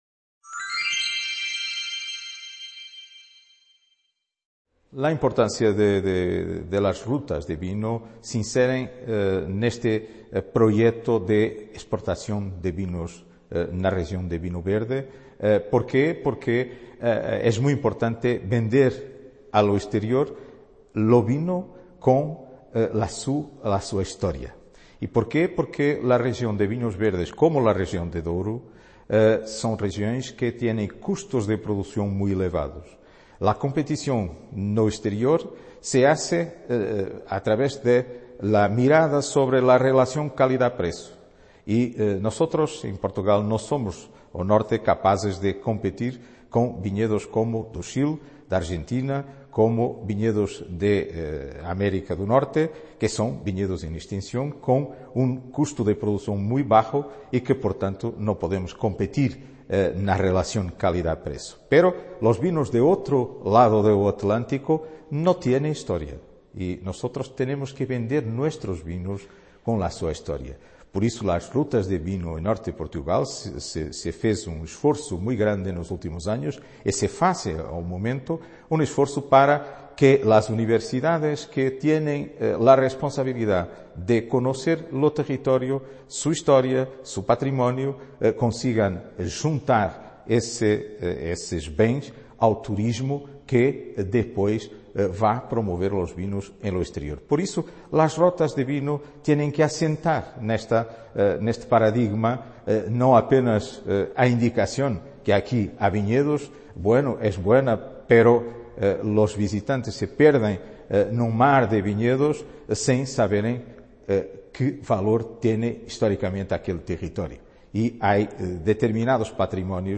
Entrevista
C.A. Ponferrada - II Congreso Territorial del Noroeste Ibérico